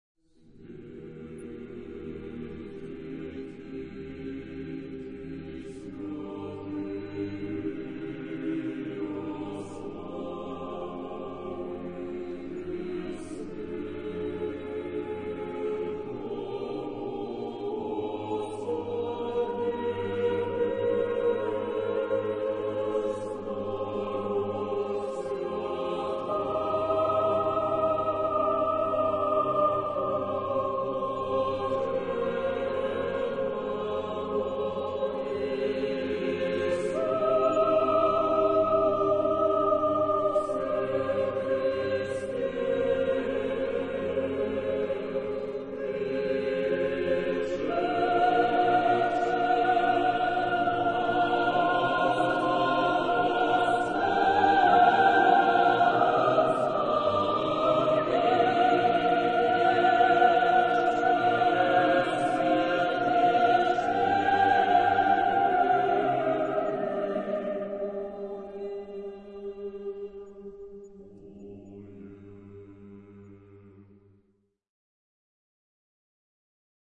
Genre-Style-Form: Sacred ; Orthodox liturgical hymn
Mood of the piece: solemn ; joyous ; prayerful Type of Choir: SSAATTBB (8 mixed voices )
Tonality: E flat major